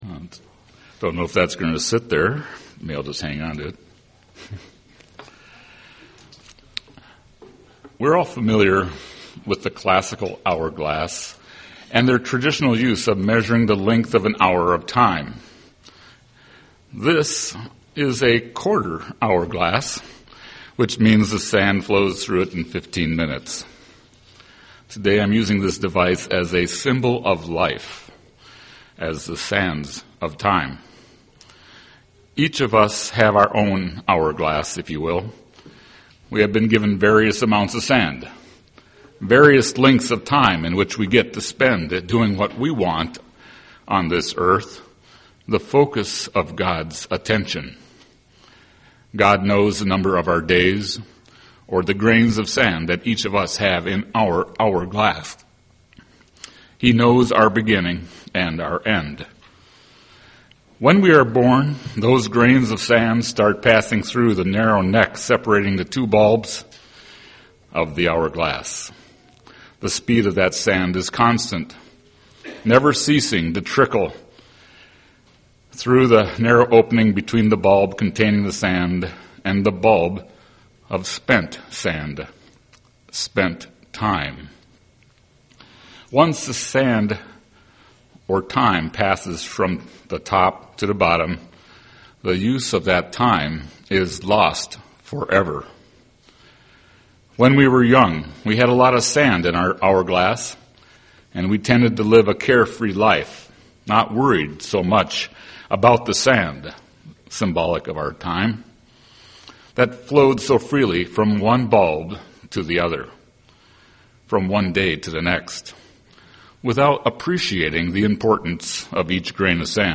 UCG Sermon time Studying the bible?